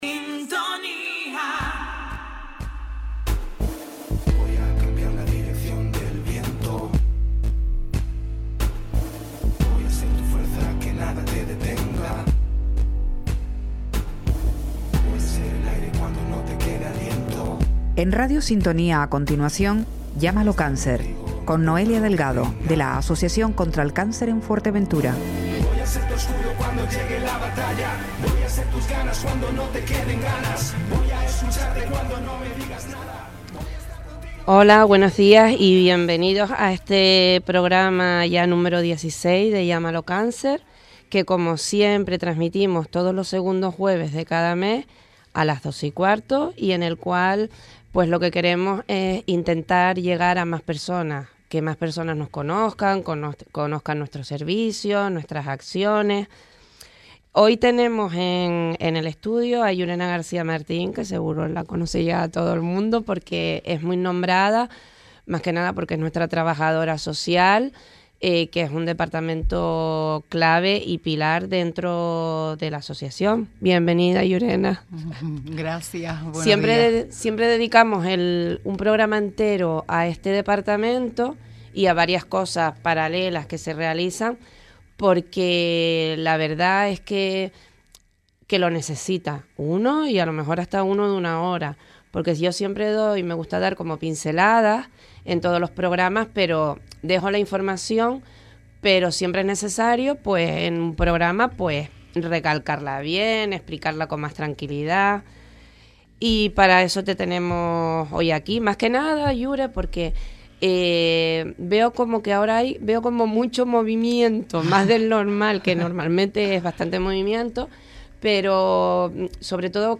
En nuestra última emisión, tuvimos el placer de recibir en el estudio